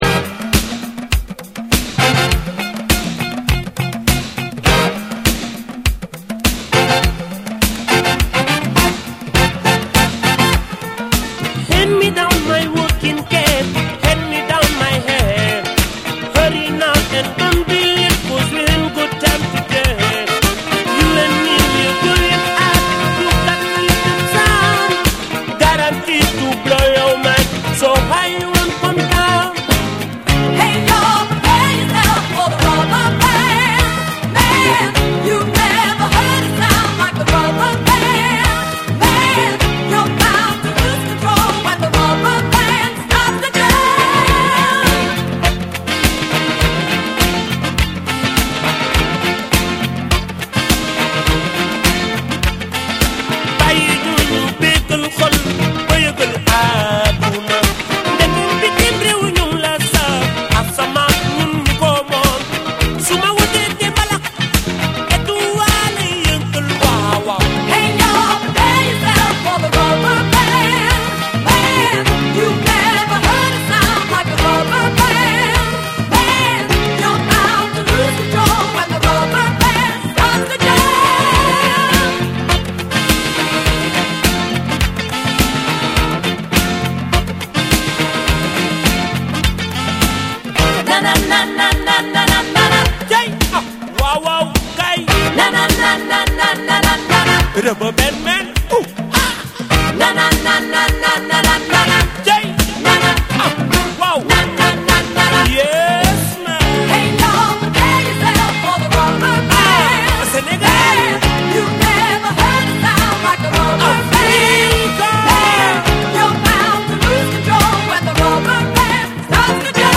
BREAKBEATS / ORGANIC GROOVE / WORLD